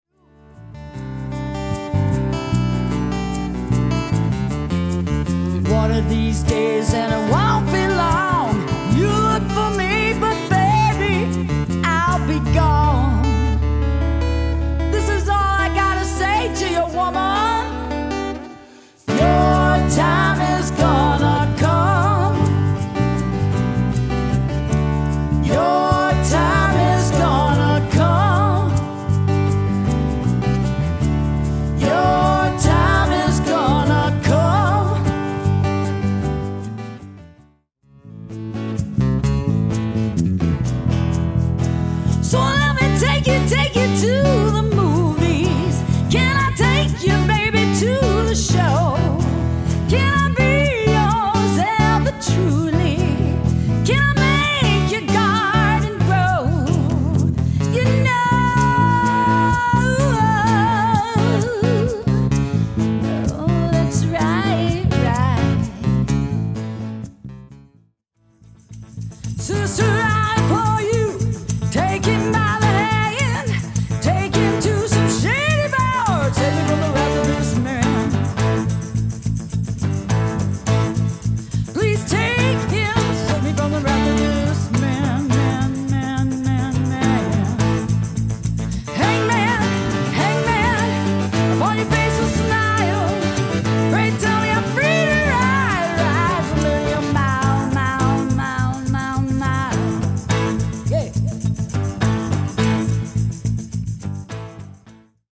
live...1 min 45 sec
Live Medley (click on picture)